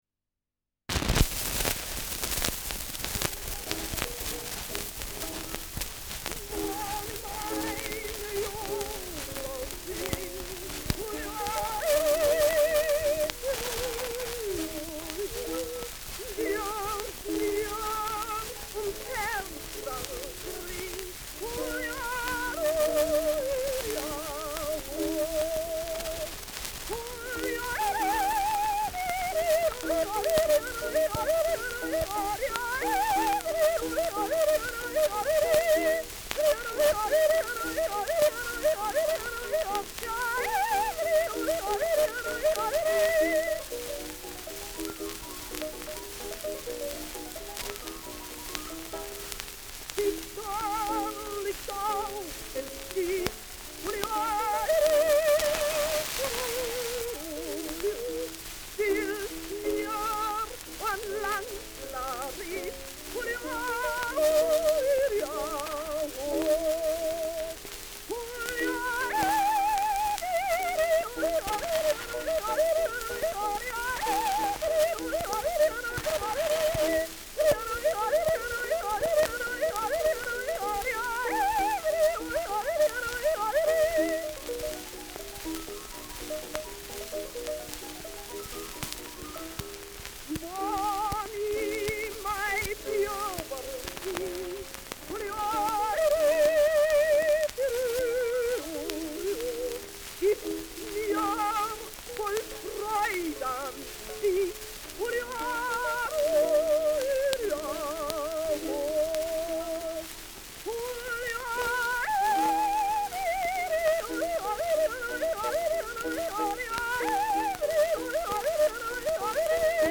Schellackplatte
Sehr stark abgespielt : Erhöhtes Grundrauschen : Häufiges Knacken : Zischen : Nadelgeräusch